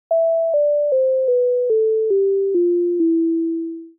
2. Verbind de message met het [line~]-object om een envelope te maken (attack-tijd van 5ms met amplitude-waarde van 1, release-tijd van 1000ms met een amplitude-waarde van 0).